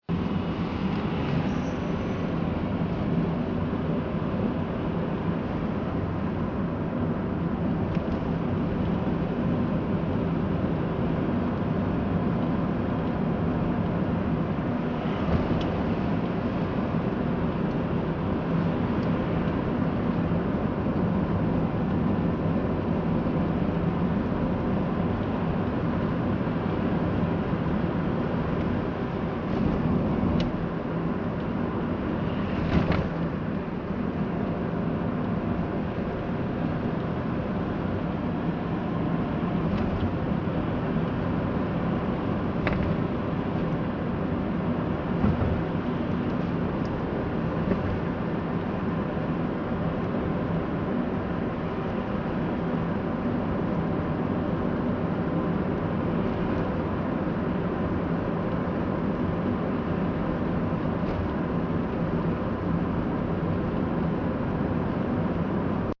Car Interior Driving